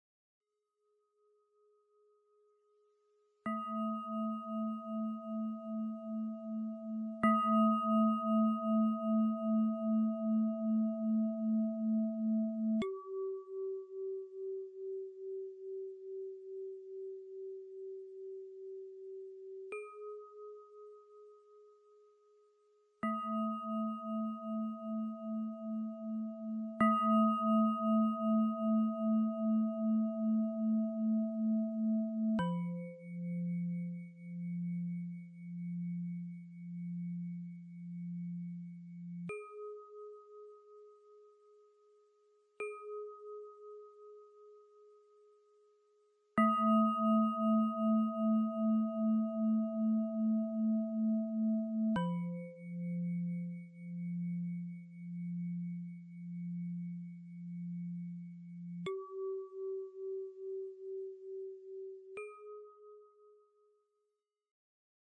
Tibetan Bowl Bells